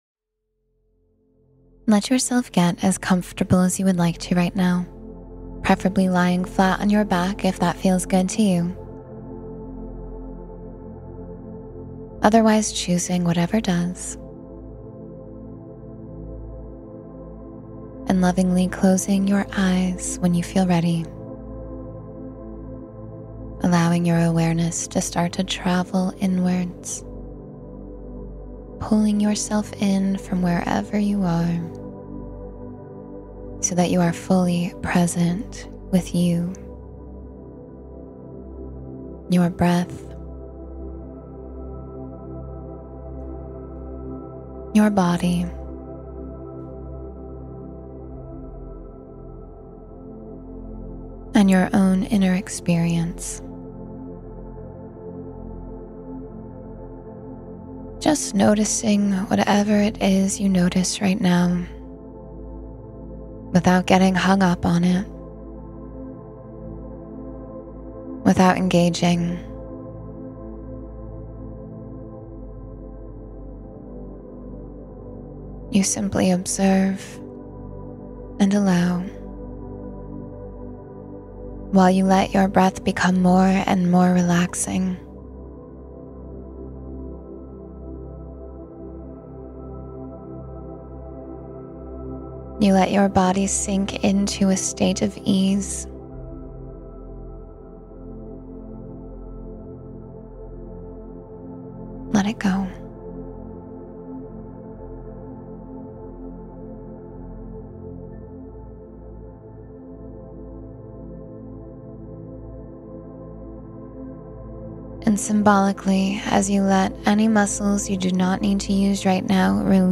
I Have Enough, and More Is Coming — Meditation for Abundance and Gratitude